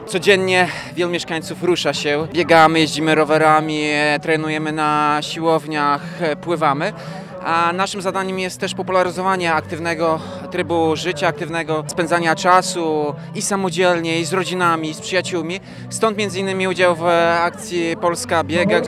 W Ełku nie brakuje imprez propagujących aktywność fizyczną, podkreśla prezydent miasta, Tomasz Andrukiewicz, który również wystartował w ramach akcji Polska Biega.